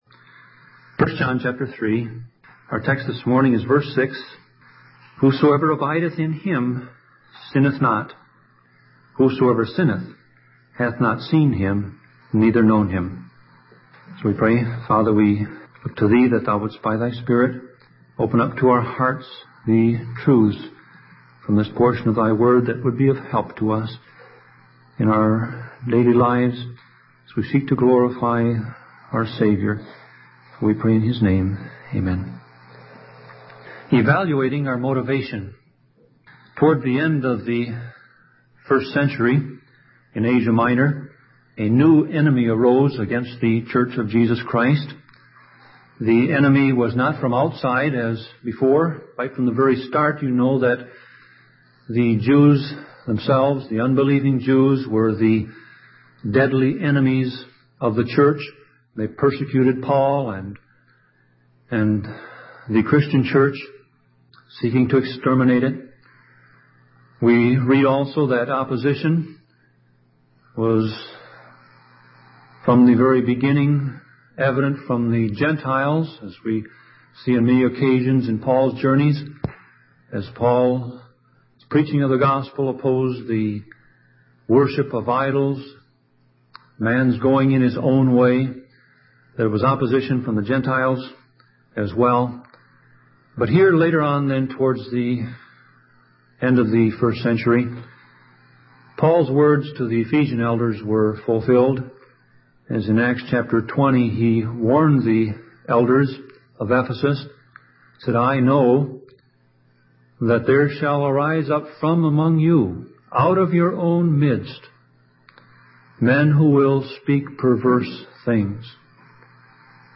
Sermon Audio Passage: 1 John 3:6 Service Type